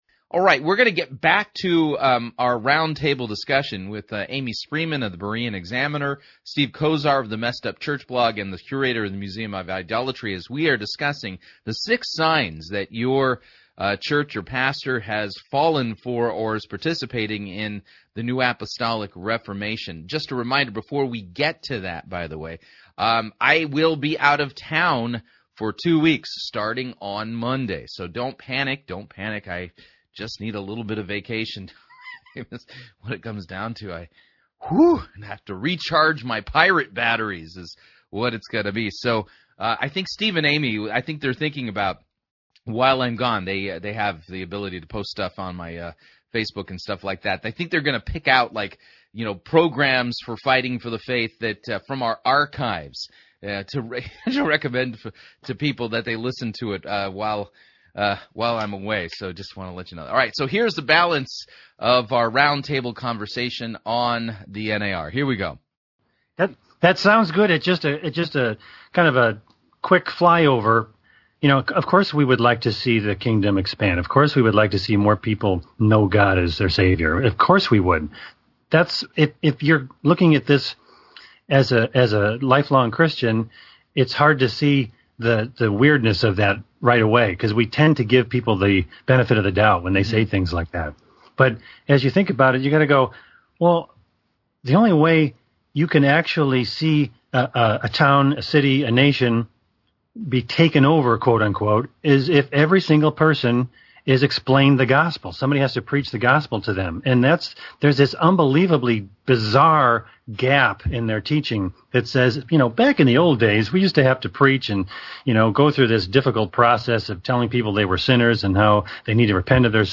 in a round table discussion on what is the N.A.R. and why it is heresy.